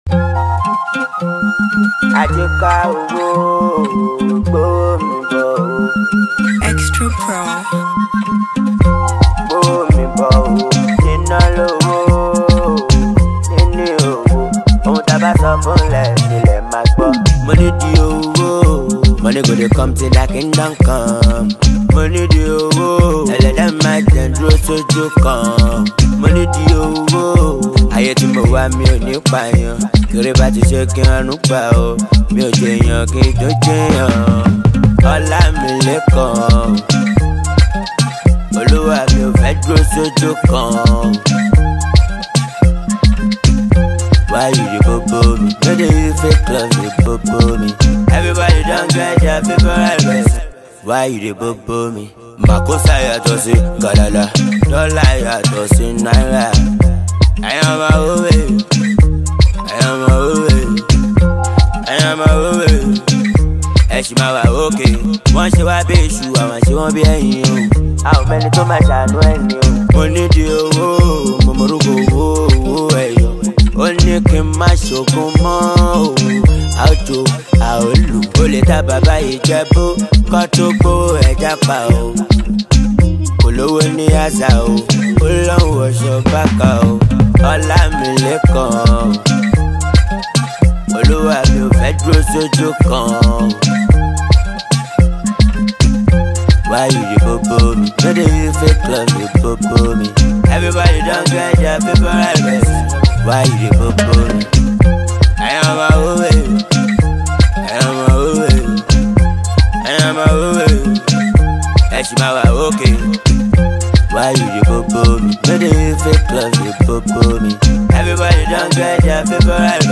singer-songsmith